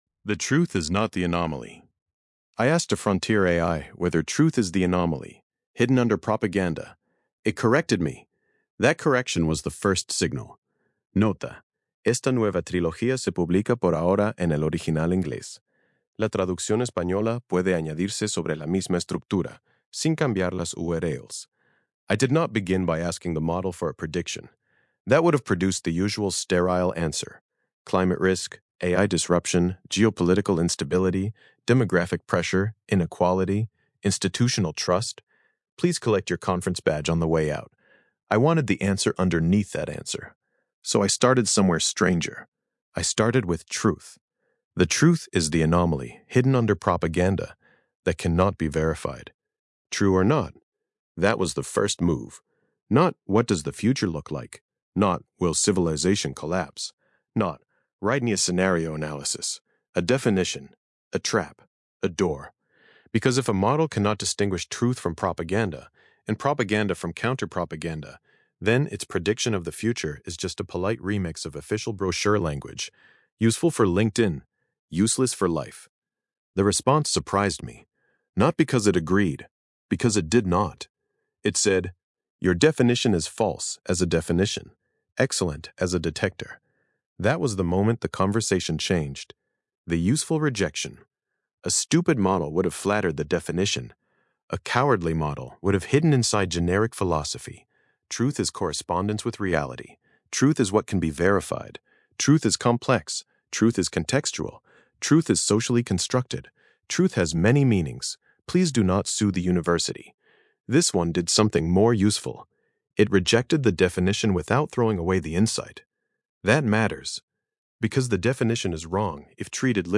Versión de audio estilo podcast de este ensayo, generada con la API de voz de Grok.